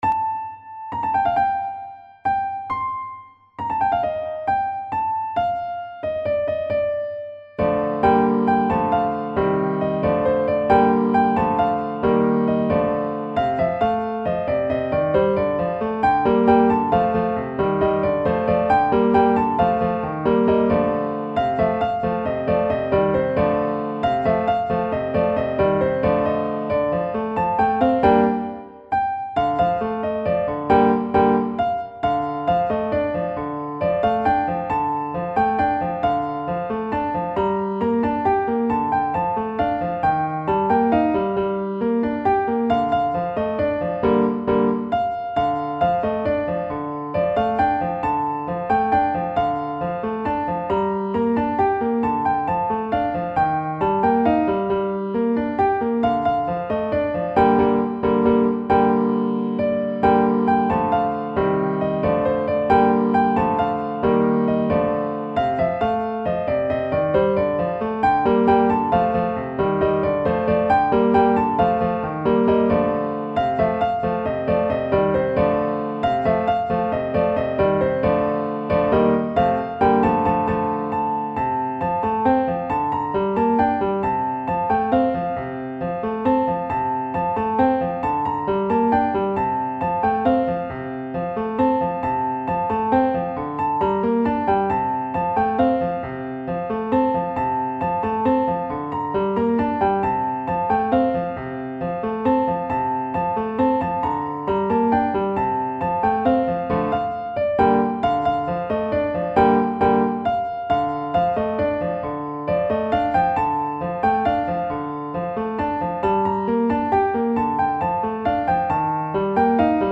نت پیانو